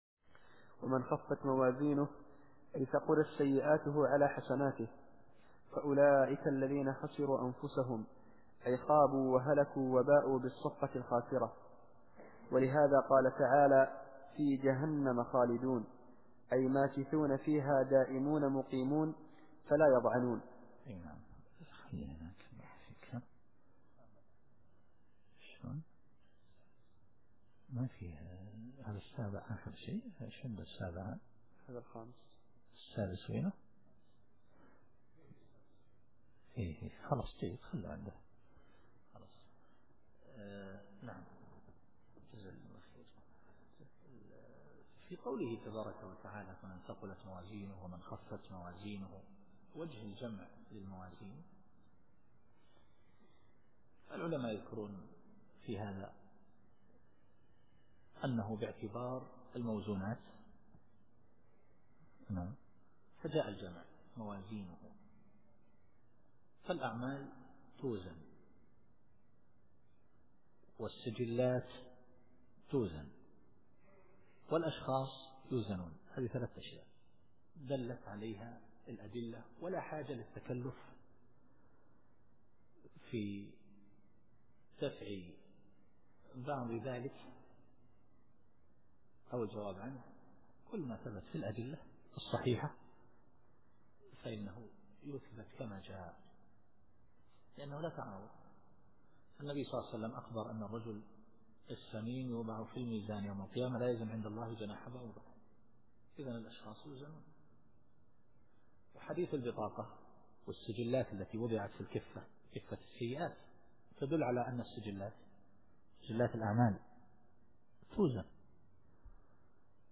التفسير الصوتي [المؤمنون / 103]